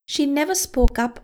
Whoomping sound!
I have a voice recording that periodically has these awful noises in it.
whoomph is blowing on the microphone, a pop shield will help prevent it.
Yes, that’s P Popping as above.